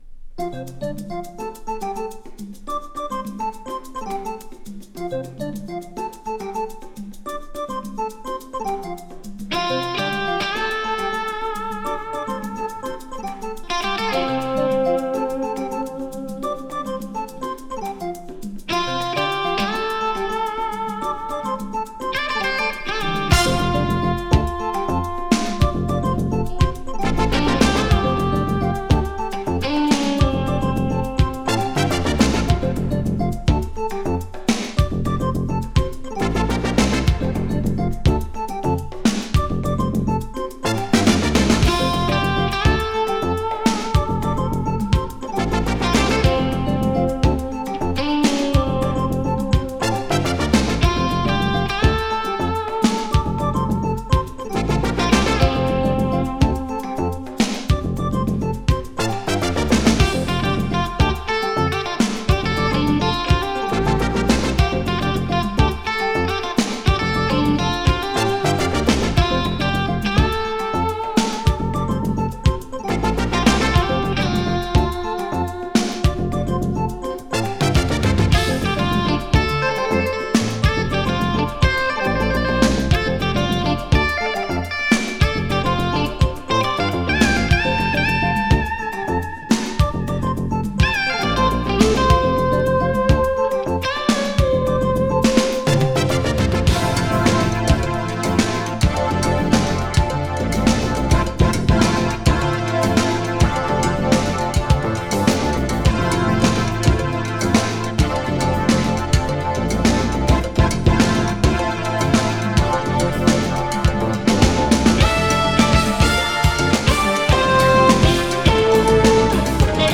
Japanese Electric Fusion
和フュージョン・シーンを代表するギタリスト。 ニューエイジなアプローチのメロウ・フュージョン
【FUSION】